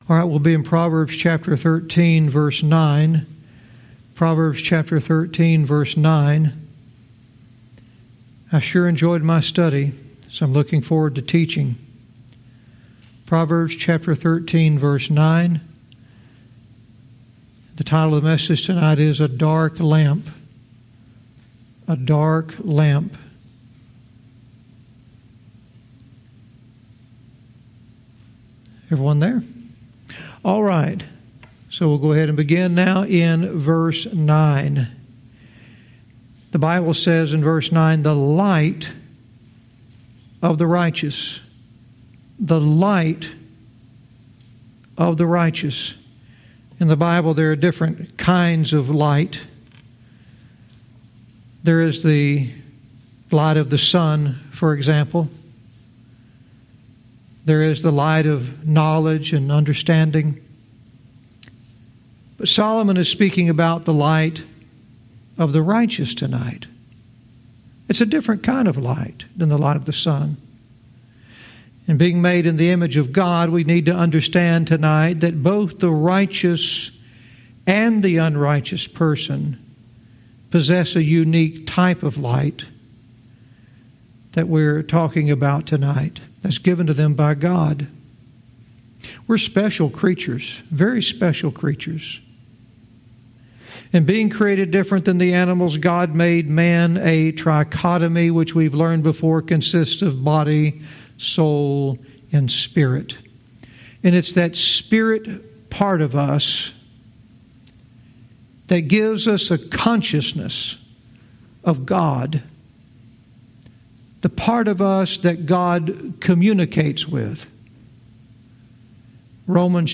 Verse by verse teaching - Proverbs 13:9 "A Dark Lamp"